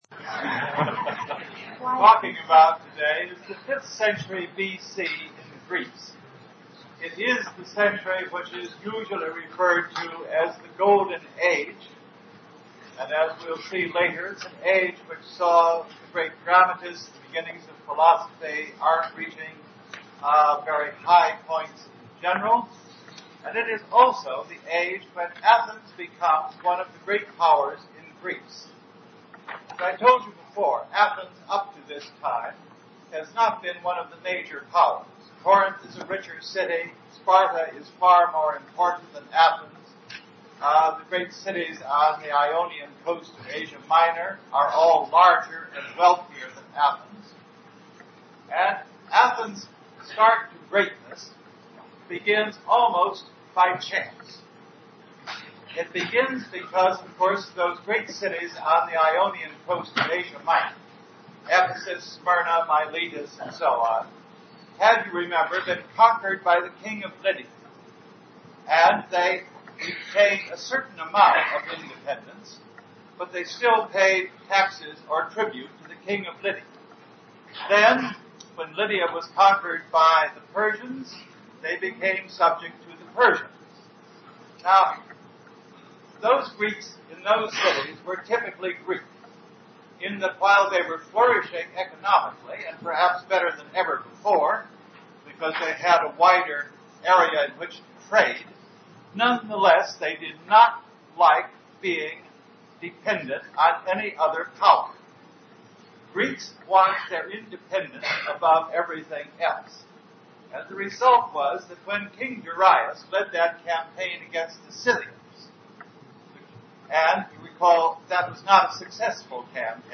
History Lectures (Restored)